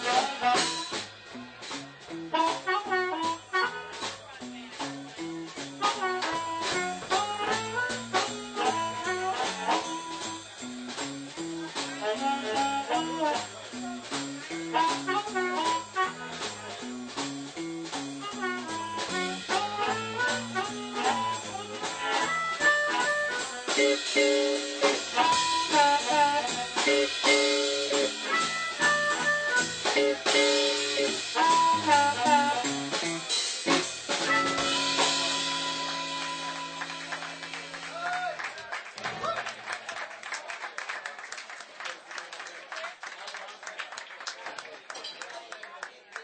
5th OTS Recital - Winter 2005 - rjt_4245